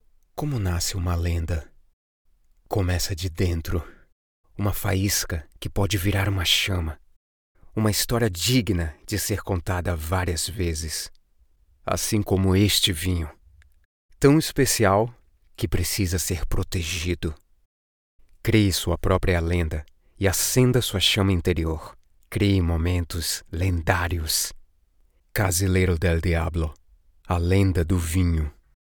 Home studio, doing this for a living since 2022, professional equipaments.
Brazilian Voiceover Talent.
Sprechprobe: Industrie (Muttersprache):